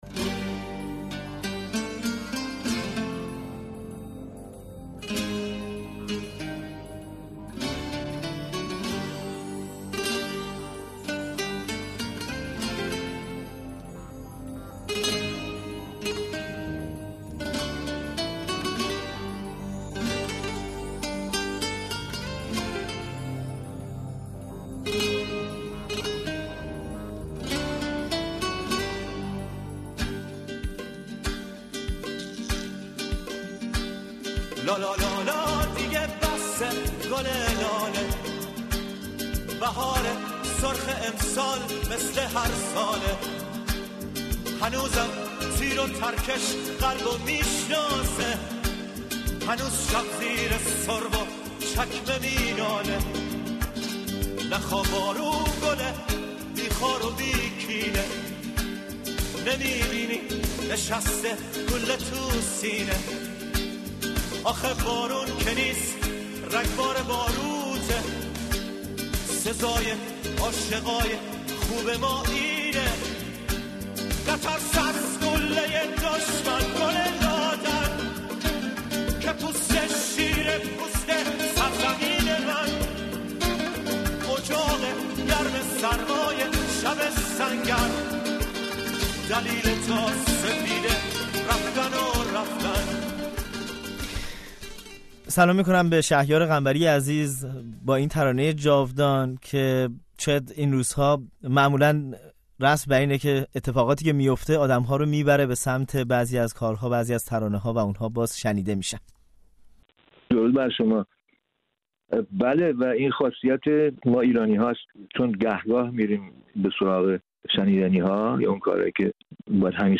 شهیار قنبری، ترانه‌سرای ایرانی، دو ترانهٔ شاخص ضد جنگ دارد، «لالا دیگه بسه» و «جنگجو». او در گفت‌وگو با رادیو فردا از تجربهٔ این دو ترانه و سایهٔ جنگ اسرائیل بر سر ایران گفته است.